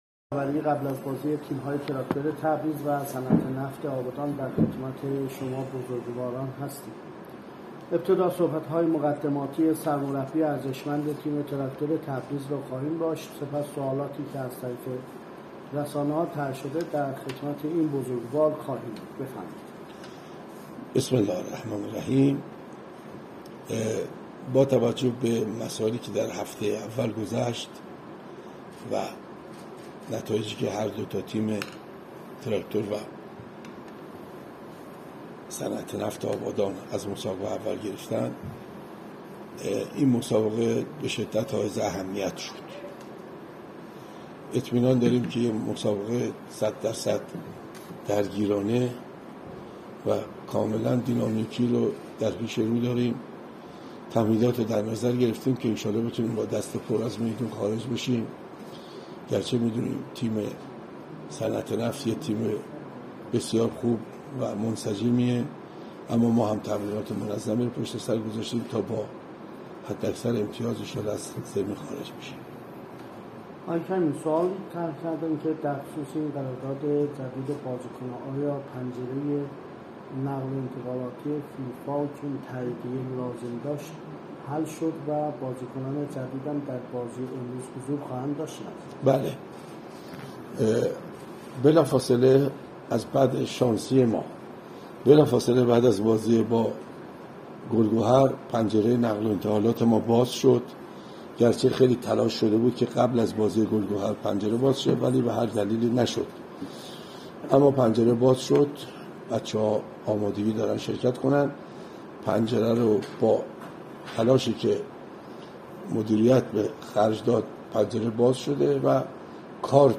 برچسب ها: نشست خبری ، لیگ برتر ، فوتبال